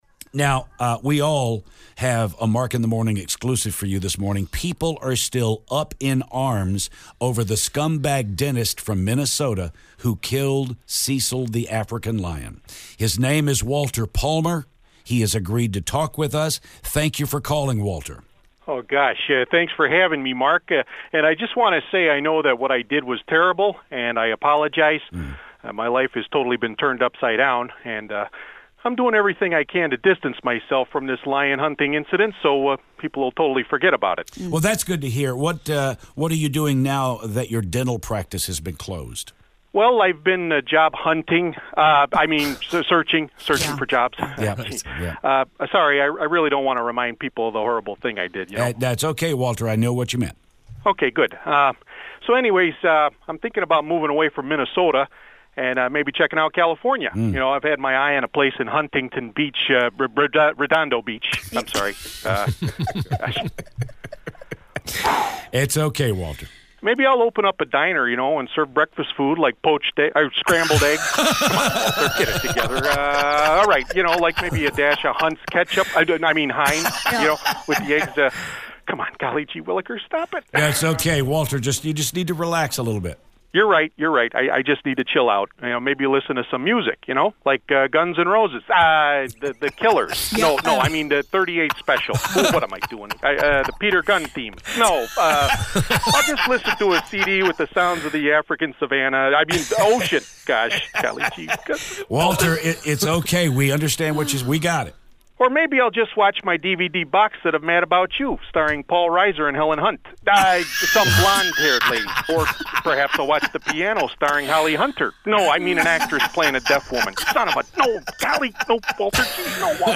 Dentist Who Shot The Lion Phoner
The d-bag Minnesota dentist who killed the lion calls the show.